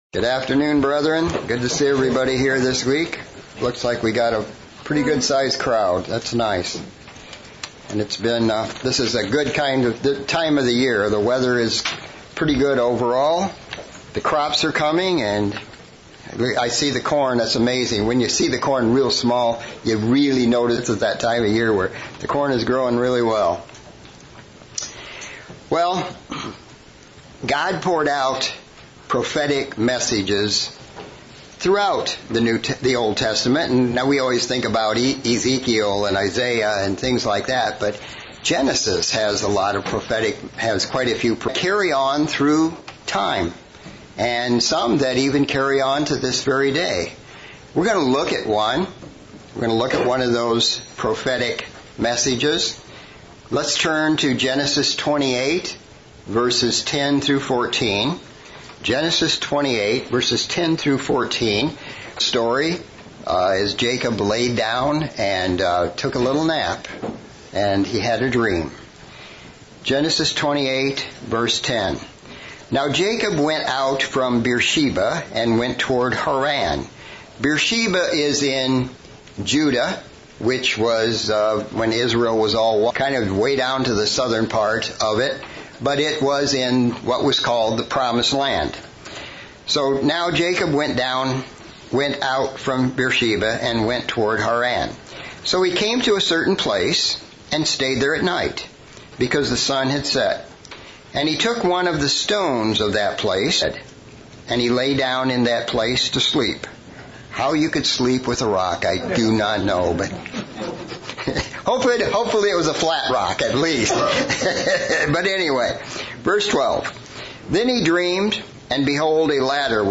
Sermon looking at the birthright promises given to Jacob and how these promises have been both a physical and spiritual blessing to the rest of the world and how they will be so again in the future.